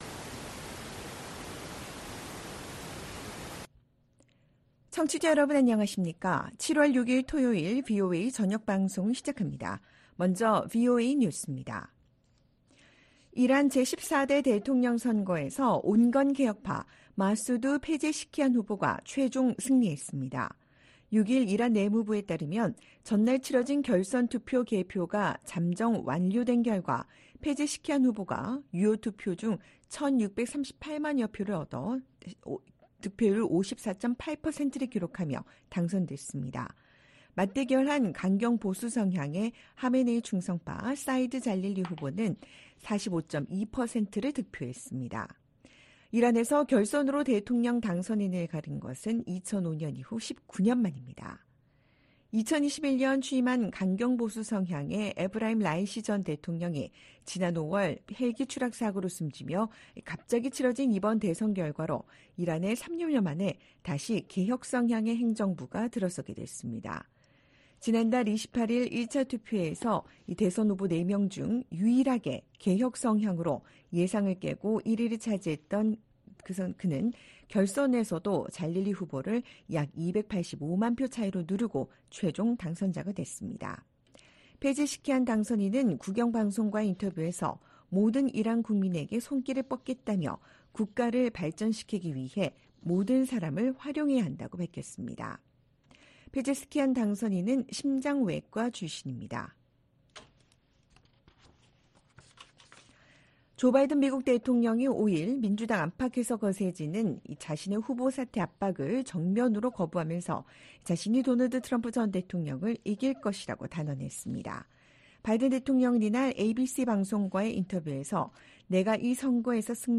VOA 한국어 방송의 토요일 오후 프로그램 1부입니다.